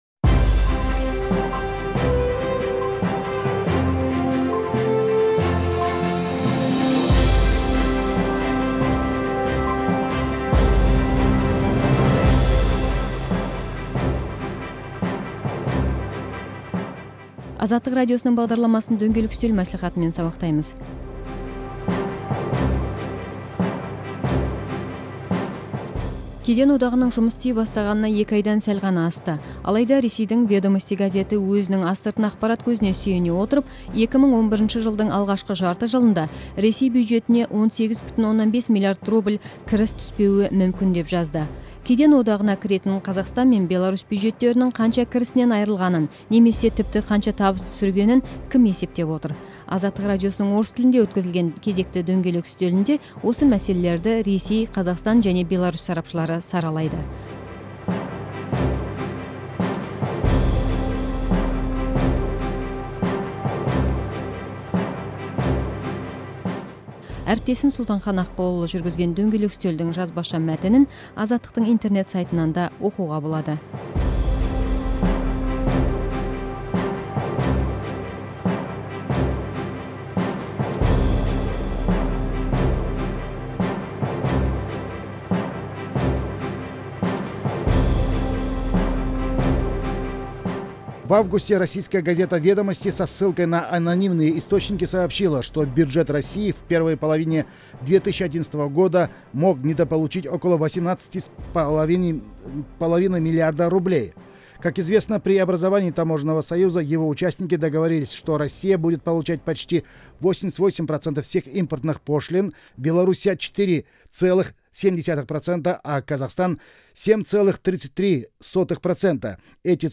Кеден одағының зияны мен пайдасы туралы сұқбатты тыңдаңыз